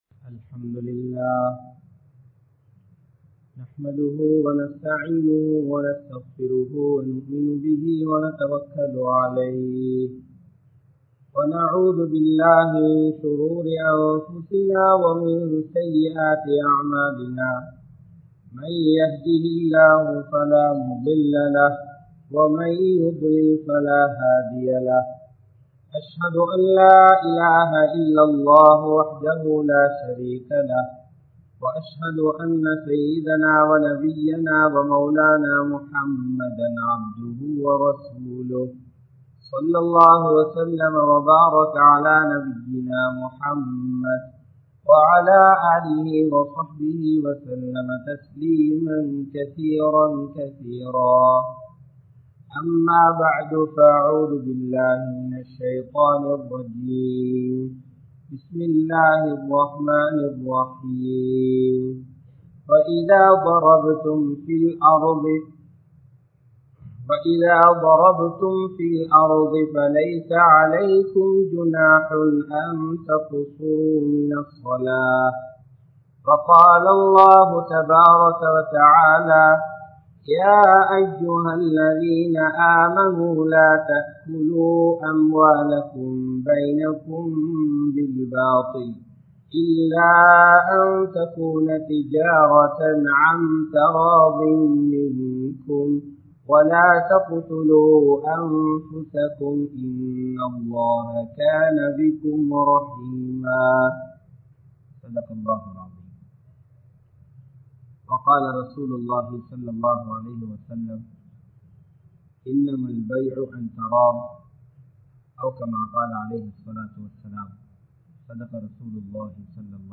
Islamiya Viyaafaara Muraihal | Audio Bayans | All Ceylon Muslim Youth Community | Addalaichenai
Colombo 12, Aluthkade, Muhiyadeen Jumua Masjidh